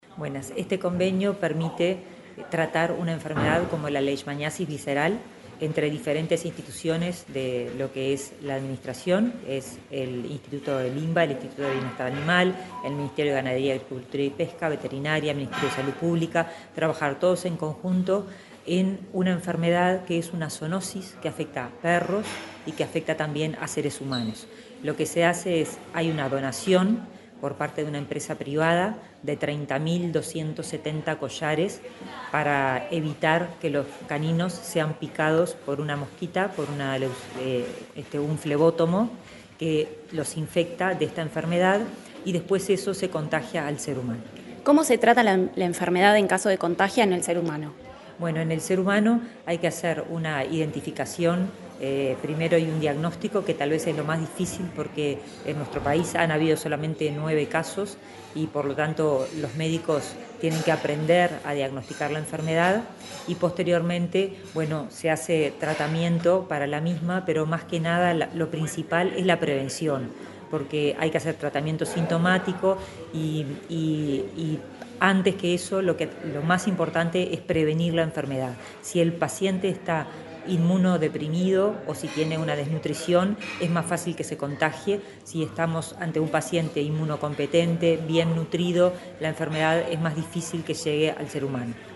Entrevista a la ministra de Salud Pública, Karina Rando
El Ministerio de Ganadería, Agricultura y Pesca (MGAP), el Instituto Nacional de Bienestar Animal, la Comisión Nacional Honoraria de Zoonosis y la Fundación Marco Podestá firmaron un acuerdo en Salto, a fin de definir la estrategia para colocar más de 30.000 collares en canes a efectos de combatir la leishmaniasis. La ministra de Salud Pública, Karina Rando, dialogó con Comunicación Presidencial acerca de la importancia de esta medida.